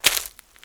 HauntedBloodlines/STEPS Leaves, Walk 25.wav at main
STEPS Leaves, Walk 25.wav